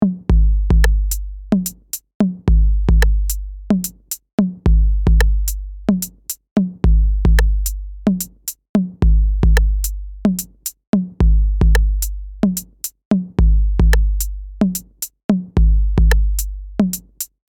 Beat
Music Beat